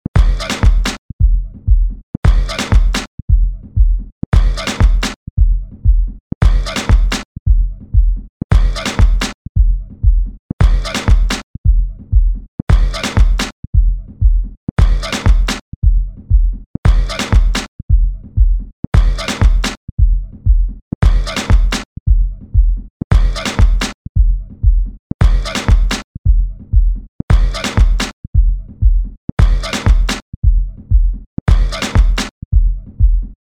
Вот тестовая фонограма, с которой я записал.